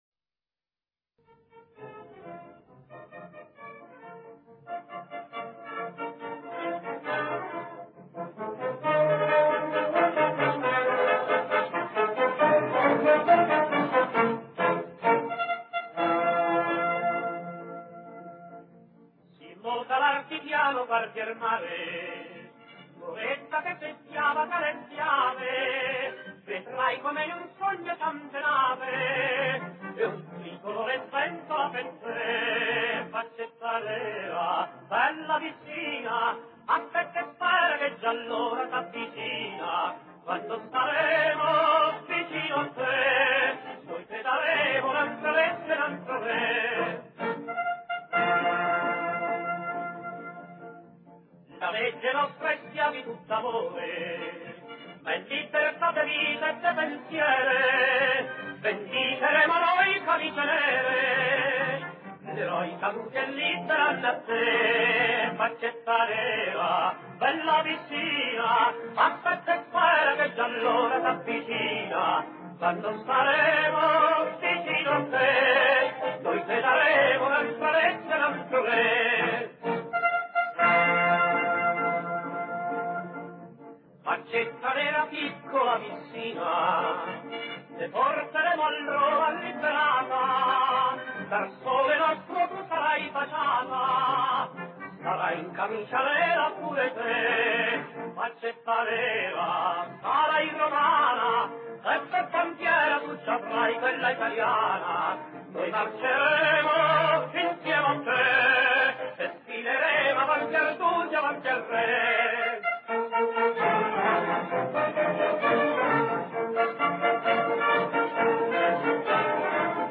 Il primo ha un carattere allegro, brillante ed aperto, mentre il secondo ha carattere triste, grigio e malinconico.”